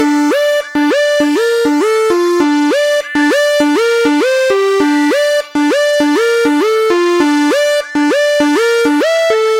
描述：紧张的警报声 不同的音高和滤波器设置。
标签： 克拉克松 合成器 预警 警报 报警 紧急情况下 警笛 警报器
声道立体声